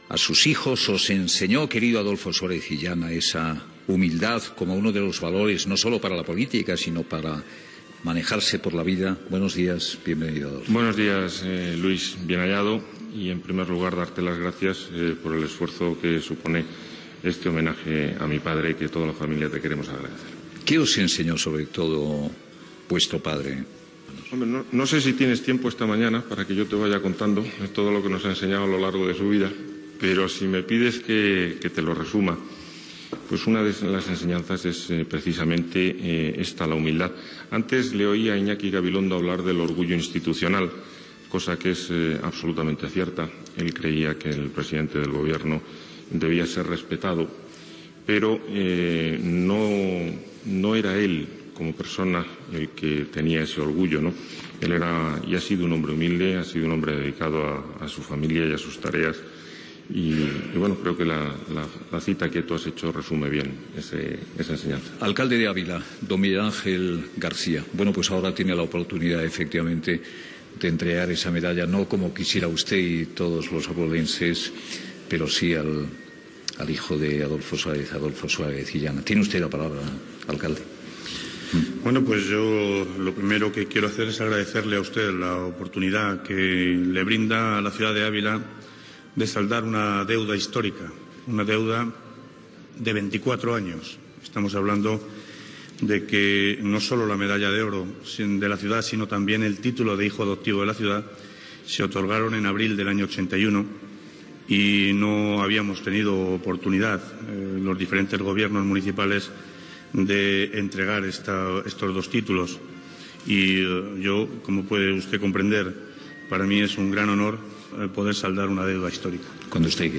Especial des del Círculo de Bellas Artes de Madrid en homenatge a l'ex president del govern espanyol Adolfo Suárez.
Info-entreteniment